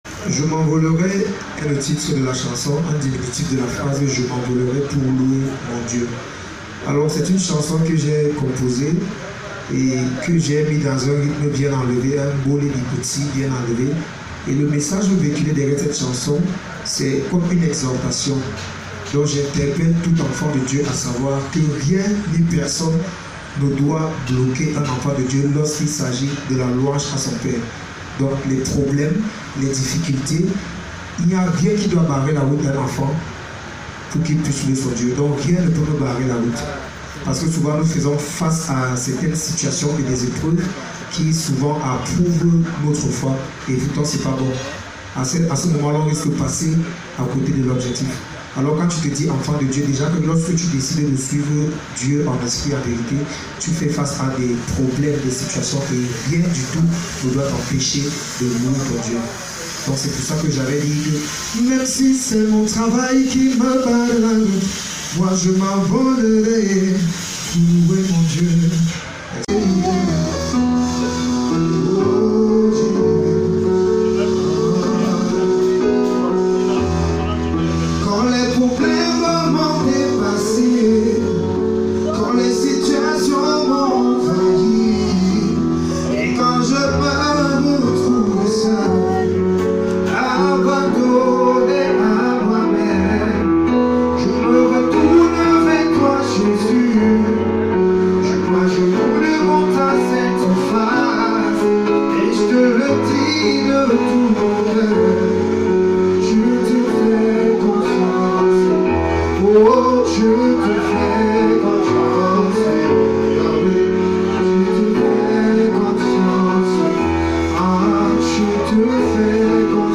Ce jeune artiste, natif de Biwong Bulu, dans le sud du Cameroun, révolutionne aujourd’hui la scène musicale grâce à sa maîtrise du Mbolé Gospel, un savant mélange de rythmes traditionnels et modernes.
fusion du Bikutsi, Benskin et autres rythmes traditionnels